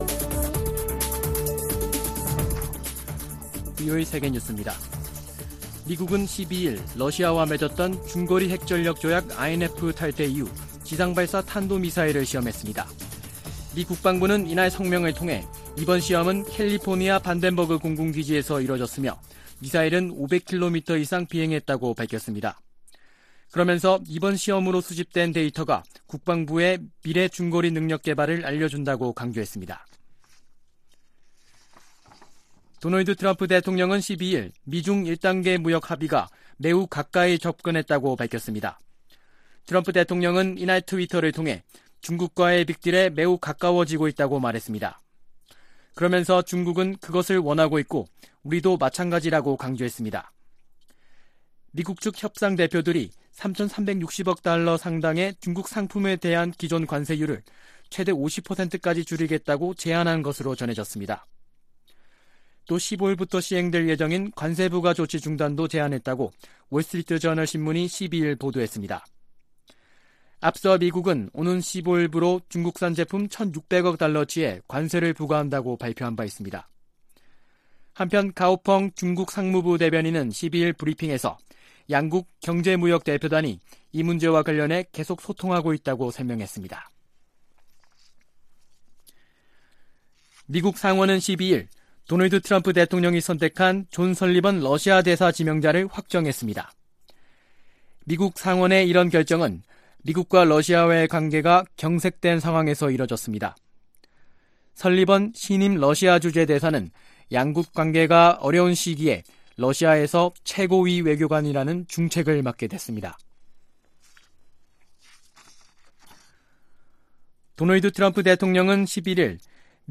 VOA 한국어 아침 뉴스 프로그램 '워싱턴 뉴스 광장' 2018년 12월 13일 방송입니다. 유엔 안보리가 어제 (11일), 미사일 발사 등 북한의 최근 도발과 향후 추가 도발 가능성에 대해 강력히 경고했습니다. 미국의 전문가들은 북한이 미-북 협상의 판이 깨져도 더 이상 잃을 것이 없다고 주장하지만, 장거리 탄도미사일이나 핵실험 도발을 한다면, 북한의 경제개발은 불가능해질 것이라고 지적했습니다.